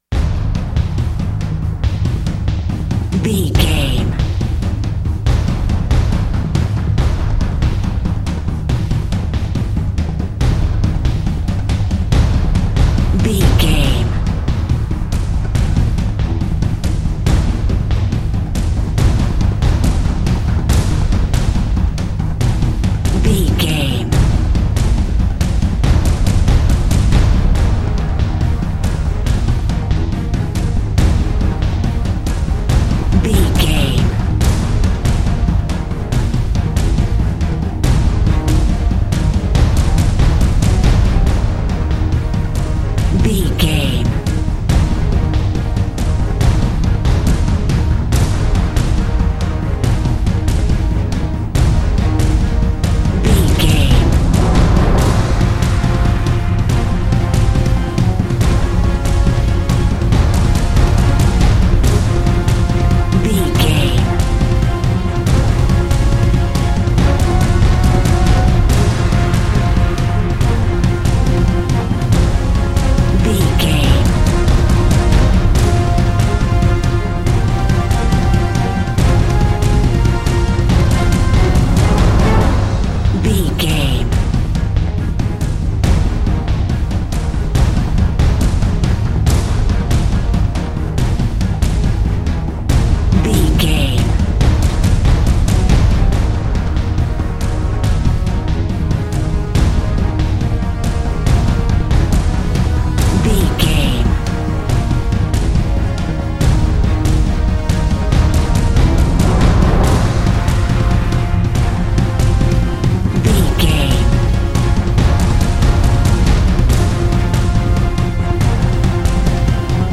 Epic / Action
Fast paced
In-crescendo
Aeolian/Minor
A♭
Fast
strings
drums
orchestral hybrid
dubstep
aggressive
energetic
intense
bass
synth effects
wobbles
driving drum beat
epic